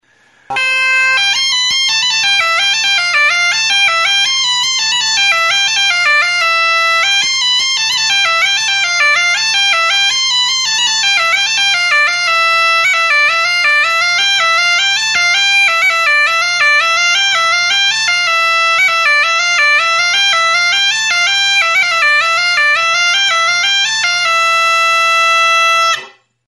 Aerophones -> Reeds -> Double (oboe)
Aerophones -> Reeds -> Single fixed (clarinet)
Recorded with this music instrument.
GAITA; BOTA; GAITA DE BOTA; XIROLARRUA